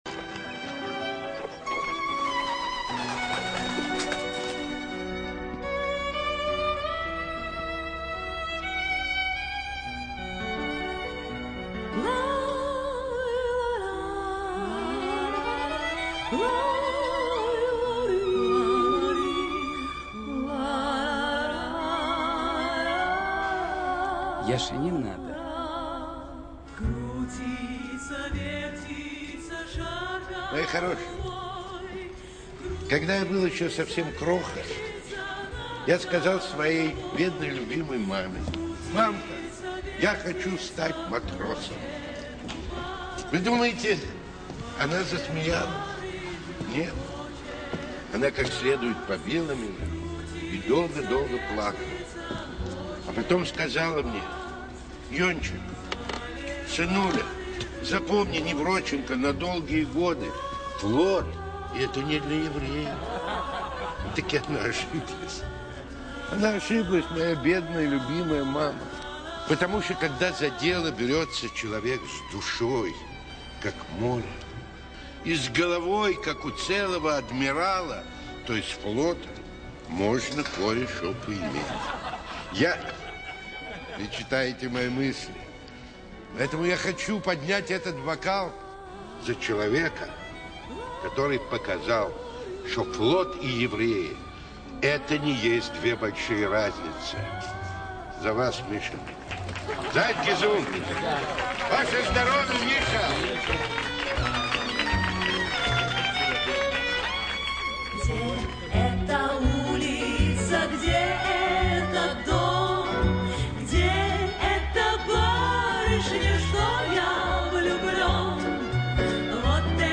Теги: музыка, нежная, лето, закат